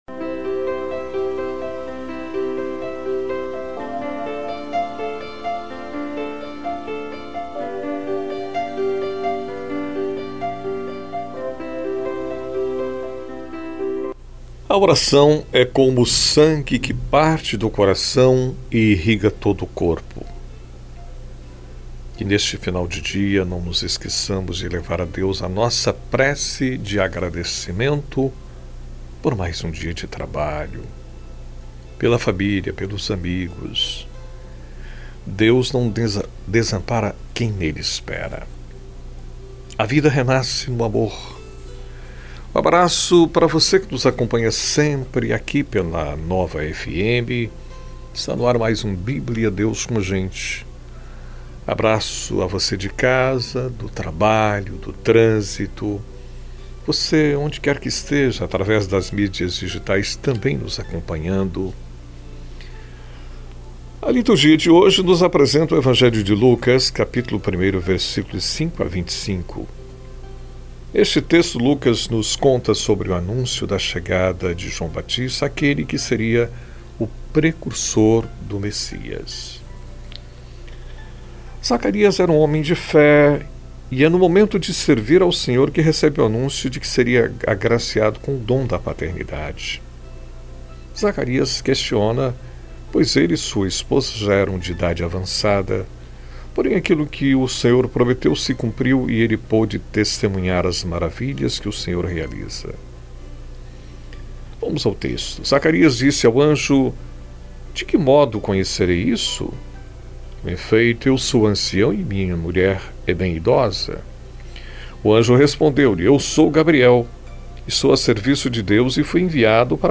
É um momento de reflexão diário com duração de aproximadamente 5 minutos, refletindo o evangelho do dia, indo ao ar de segunda a sexta, na voz do locutor